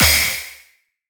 normal-hitfinish.wav